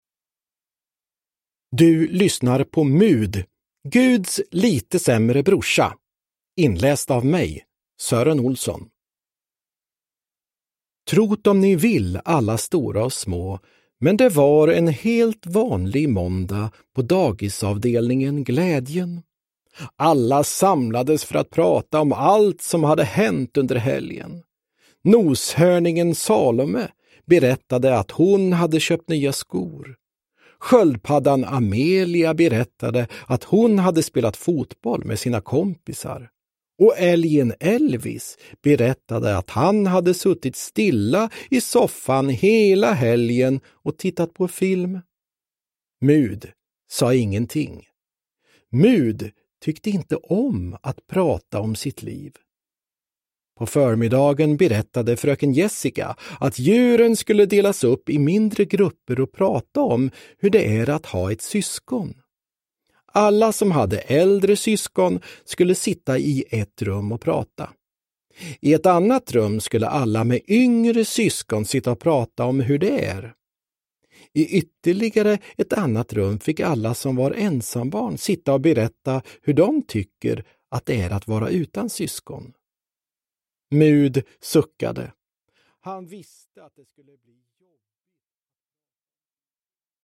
Mud. Guds lite sämre brorsa – Ljudbok – Laddas ner
Uppläsare: Sören Olsson, Anders Jacobsson